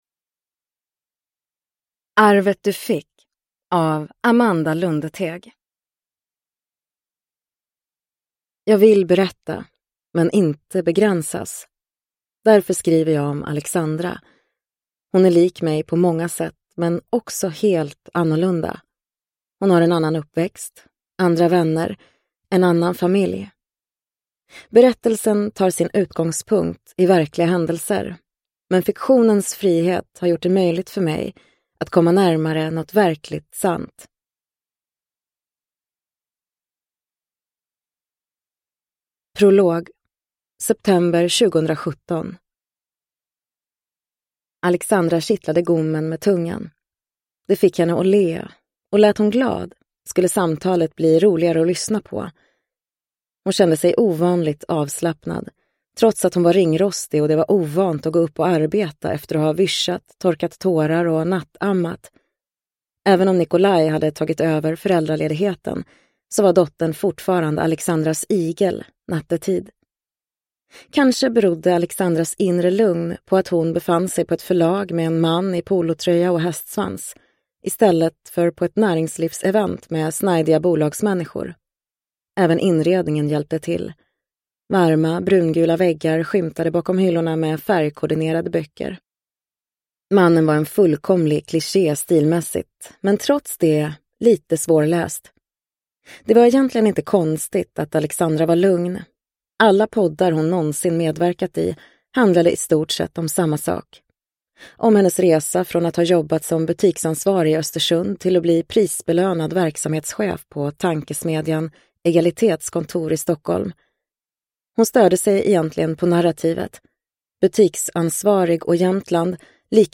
Arvet du fick – Ljudbok – Laddas ner